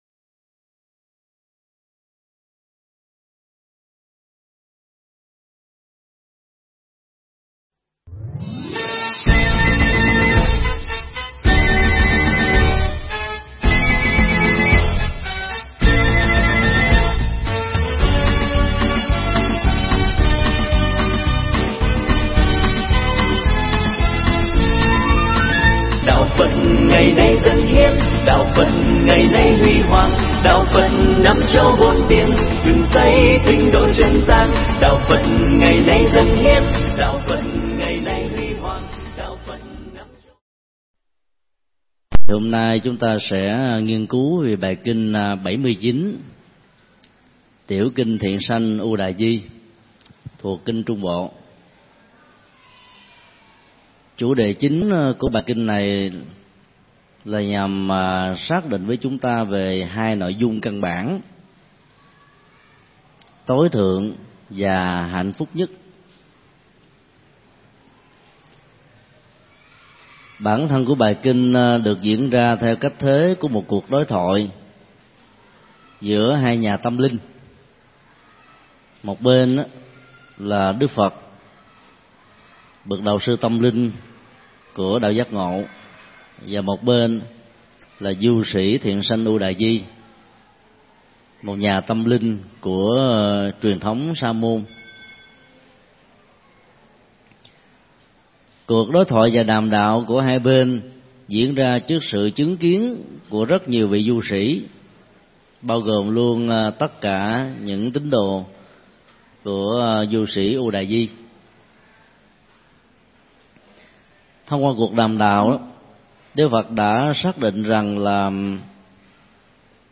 Tải mp3 Thuyết Giảng Kinh trung bộ 79: Hạnh phúc nhất
Giảng Tại Chùa Xá Lợi